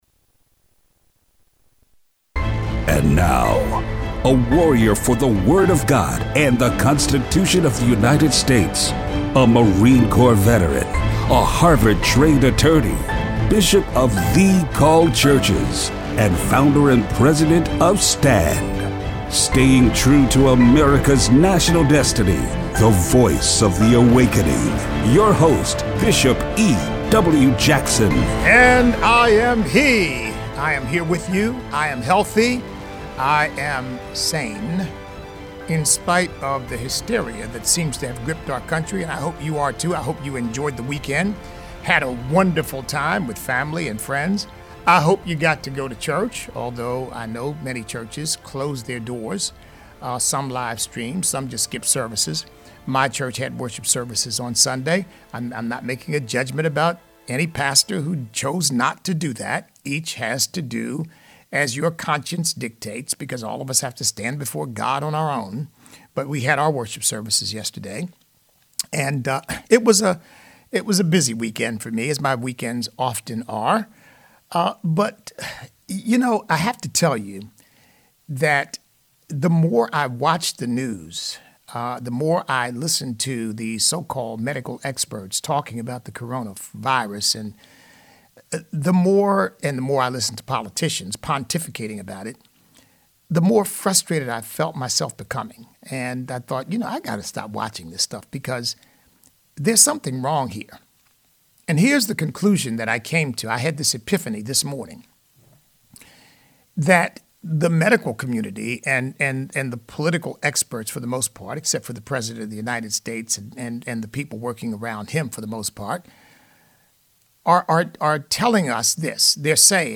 Show Notes The mainstream media is deliberately spreading fear about the Coronavirus to further their agenda. Listener call-in.